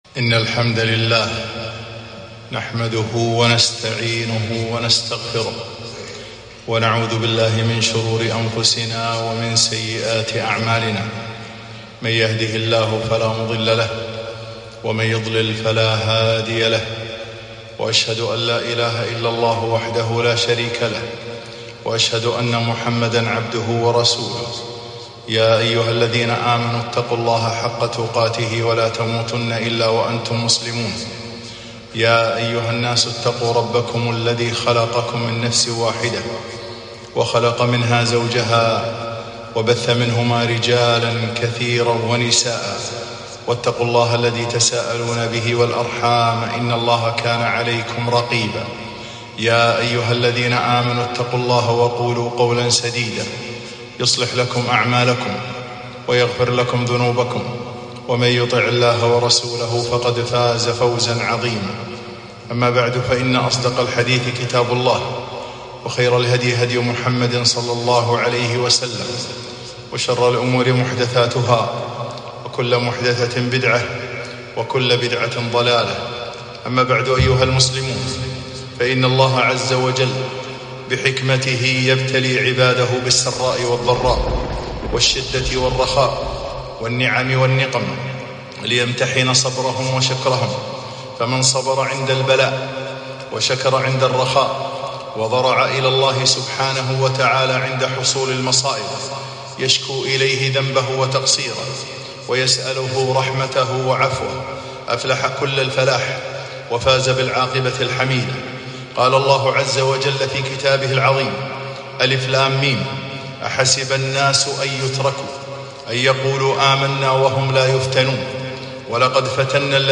خطبة - الأعتبار من الزلازل والمحن - دروس الكويت